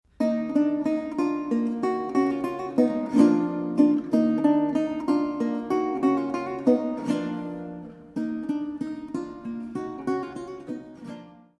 Renaissance and Baroque Guitar
Evropská kytarová hudba z 16. a 17. století
Kaple Pozdvižení svatého Kříže, Nižbor 2014